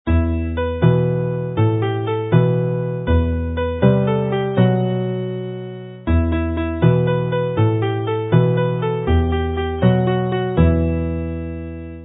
canu + offeryn